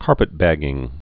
(kärpĭt-băgĭng)